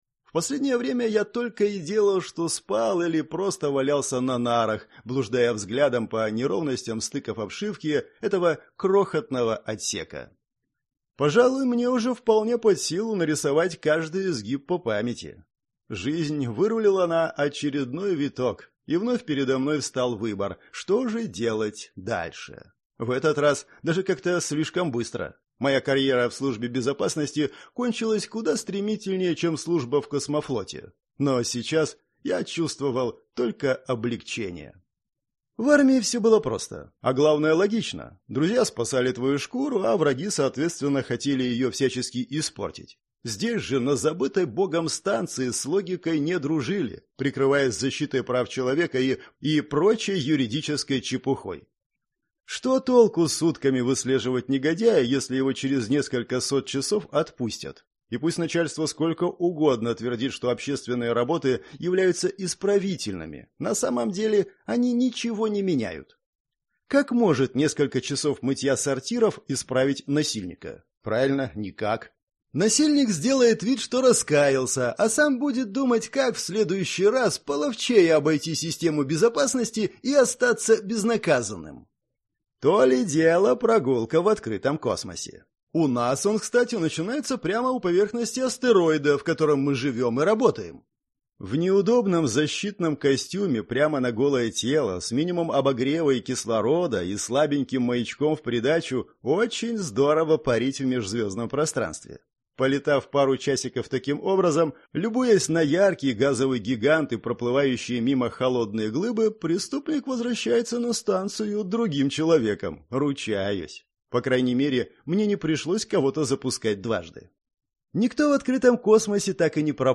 Аудиокнига Хьюстон! У нас п…